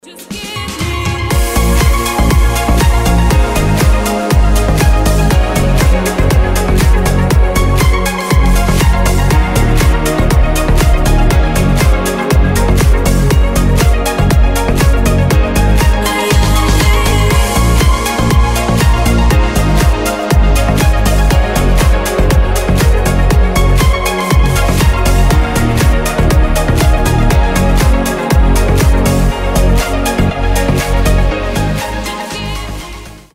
deep house
мелодичные